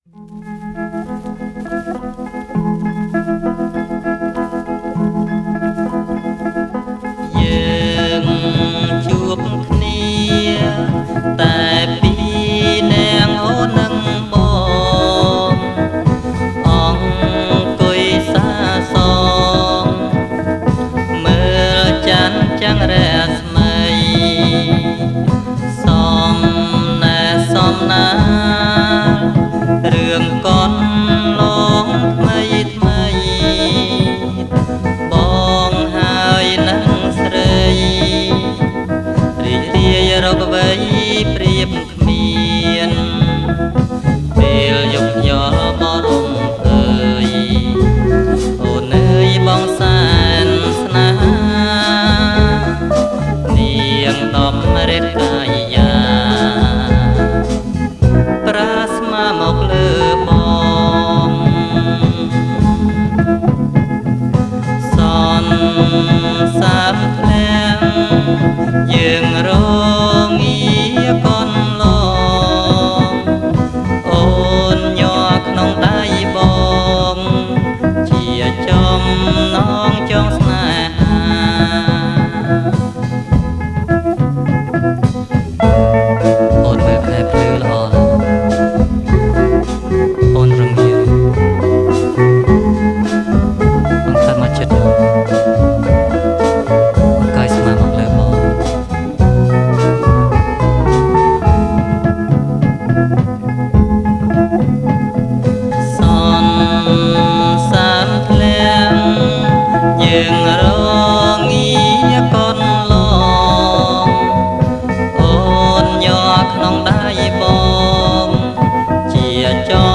• ប្រគំជាចង្វាក់ Slow+Jerk